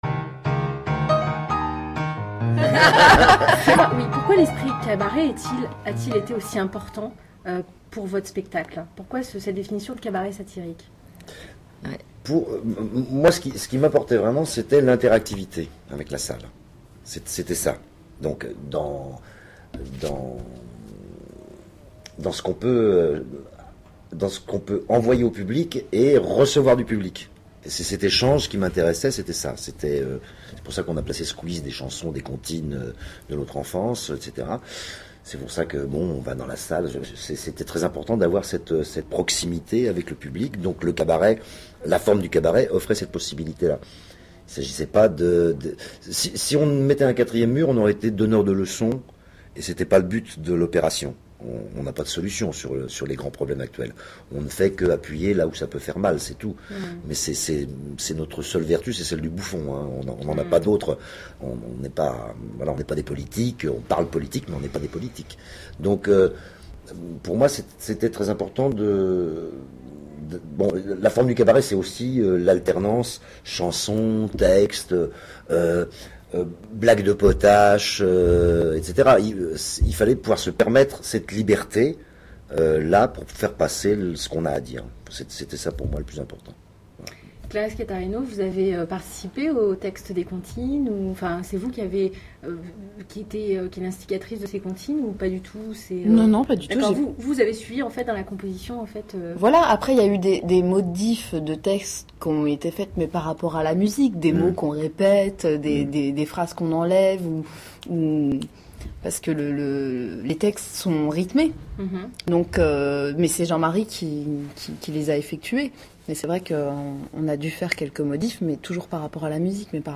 Au Bonheur des hommes, interview, épisode 2.mp3 (5.54 Mo)